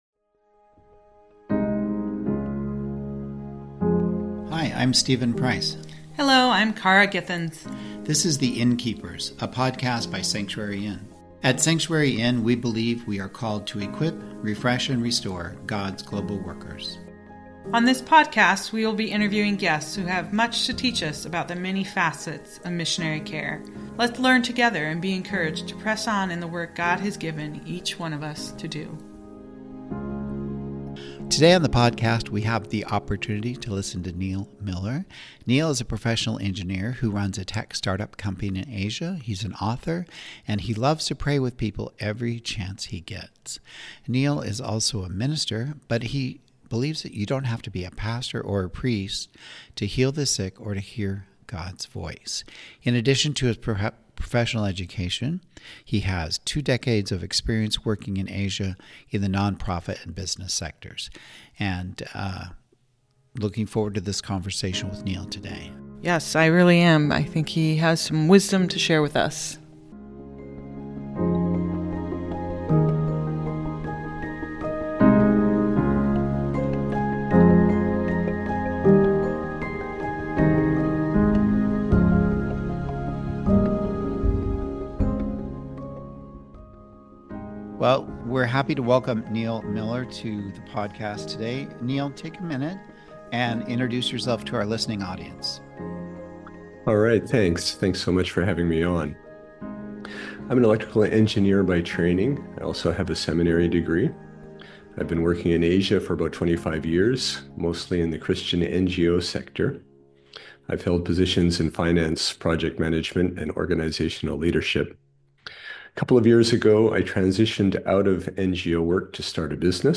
On this podcast we will be interviewing guests who have much to teach us about the many facets of Missionary Care.